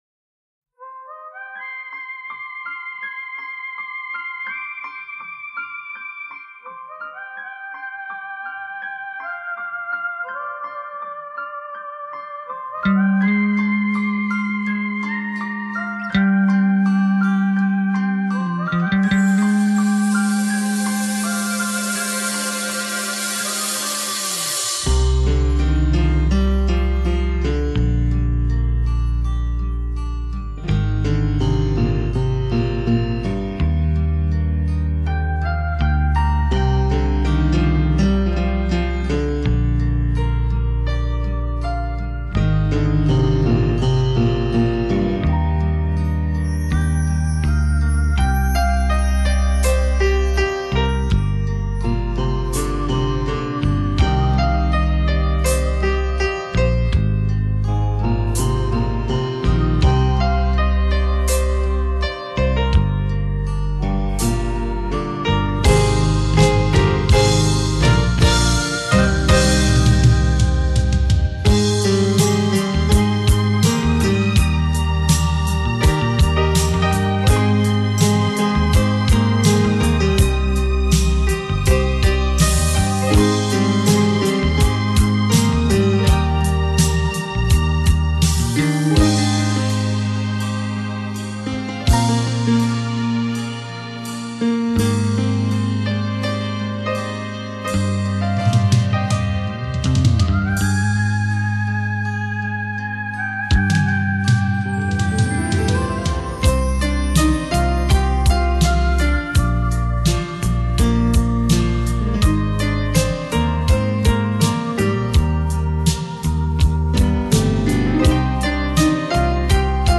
黑胶3D音效
品位完美无暇的音乐，唯美典雅的钢琴世界，
给人一种清透心扉的淡雅之美……